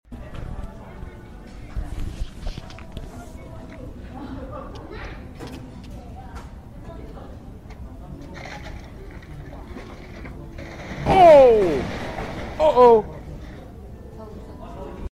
Icicle falls off skylon tower sound effects free download